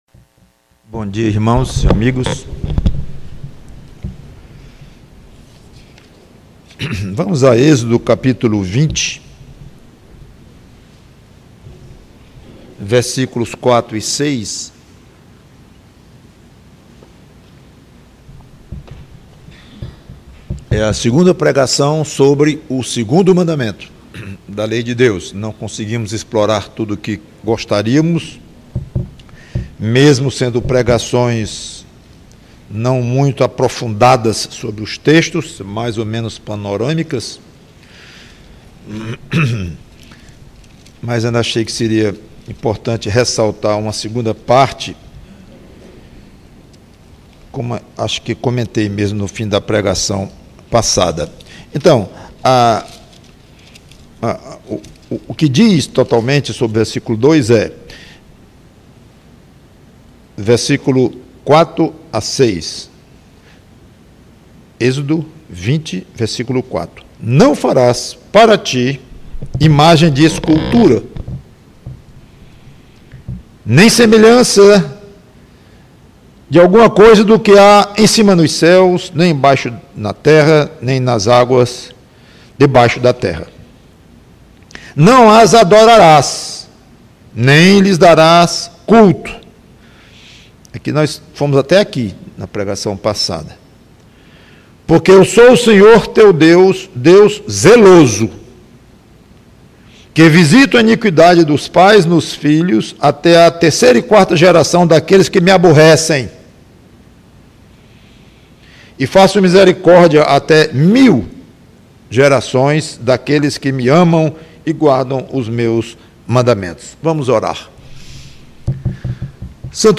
PREGAÇÃO O segundo mandamento: Deus ciumento, como?